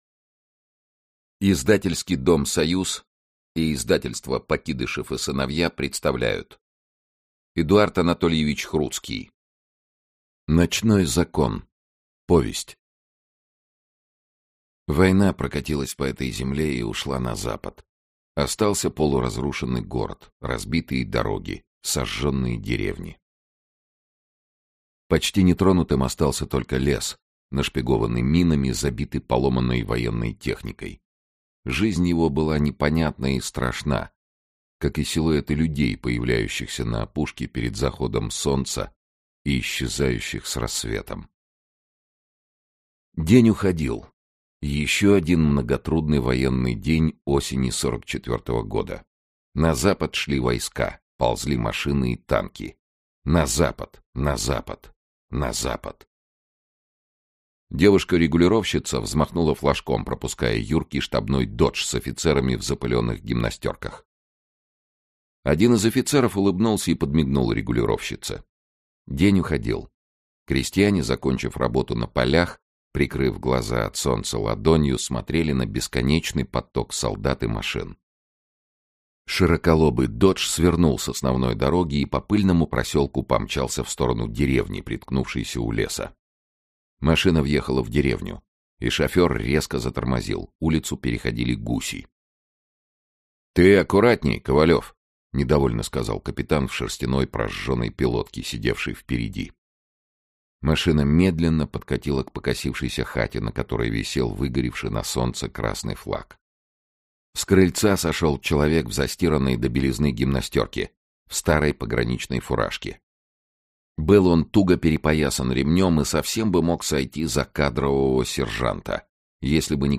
Аудиокнига Ночной закон | Библиотека аудиокниг
Aудиокнига Ночной закон Автор Эдуард Хруцкий Читает аудиокнигу Сергей Чонишвили.